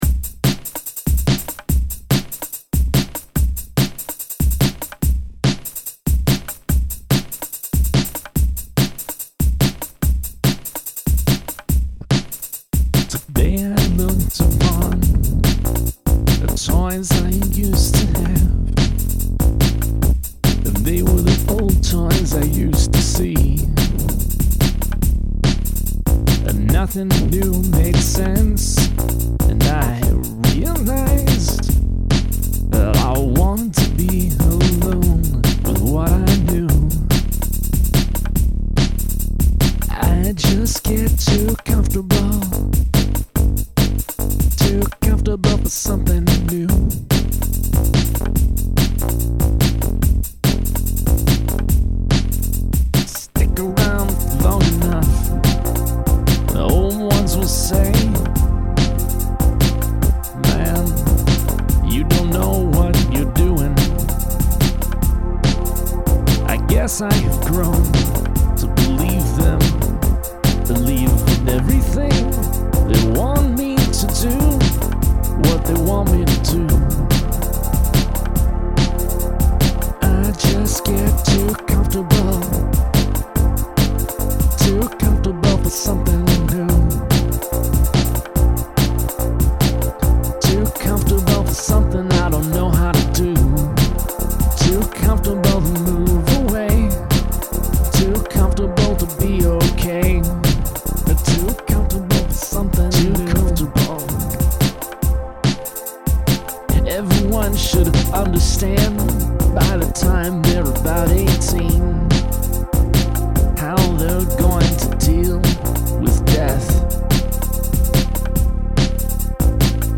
I approve of this MIDIlicious drum and pad direction, and also that you are singing outside your usual modes.
This is awesome, I love the story and the poetry, and the booty-shakin beat.